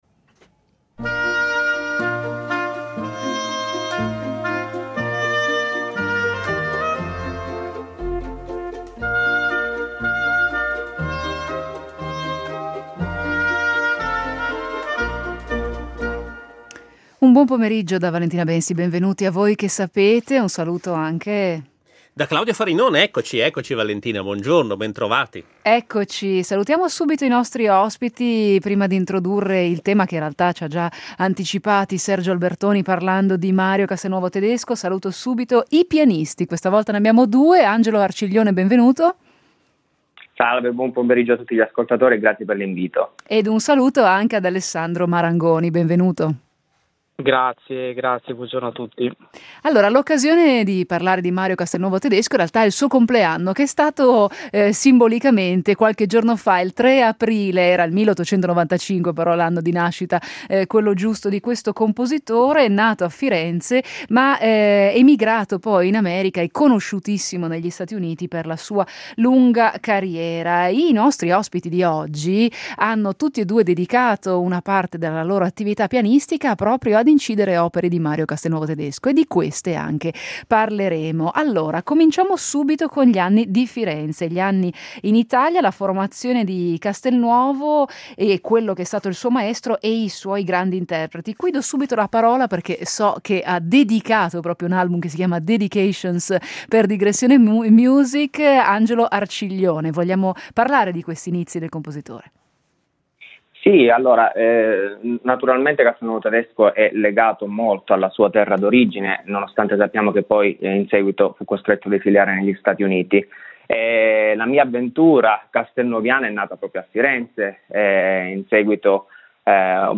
con due ospiti che si sono occupati a lungo dell’arte del compositore italiano emigrato in America